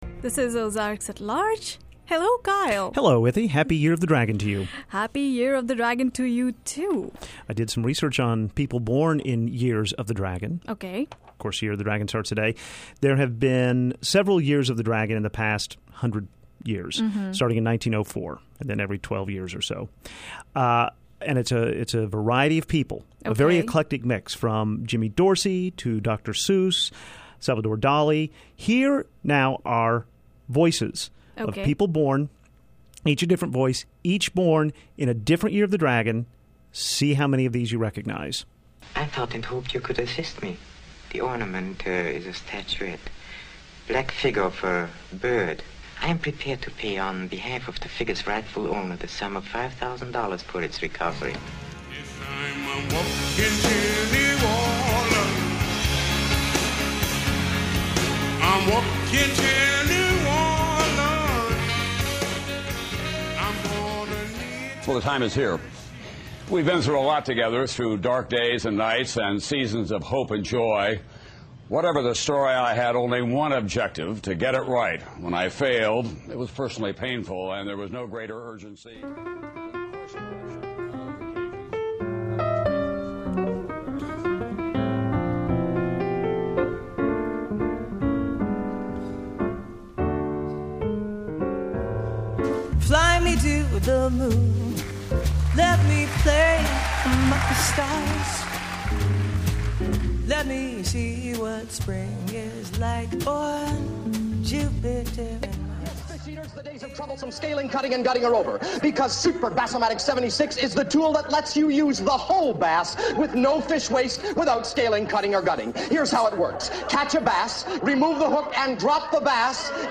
1) Peter Lorre (from the Maltese Falcon). Born in 1904 2) Fats Domino (singing Walkin’ to New Orleans). Born in 1928 3) Tom Brokaw (from his final night as anchor). Born in 1940. 4) Diana Krall (singing Fly Me to the Moon from her CD, The Very Best of Diana Krall). Born in 1964. 5) Dan Ackroyd (on Saturday Night Live in 1976). Born in 1952. 6) Tom Jones (singing It’s Not Unusual from the CD, Along Came Jones). Born in 1940. 7) Dick Wilson (as Mr. Whipple). Born in 1916.